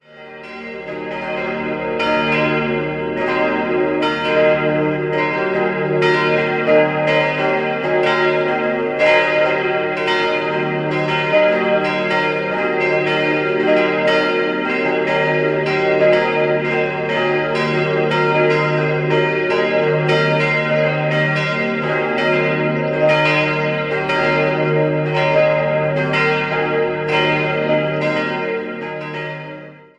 Bei der Pfarrkirche handelt es sich um einen neubarocken Bau, der in den Jahren 1903/04 errichtet wurde. 4-stimmiges Salve-Regina-Geläut: es'-g'-b'-c'' Die Glocken wurden im Jahr 1992 von der Gießerei Bachert in Kochendorf gegossen.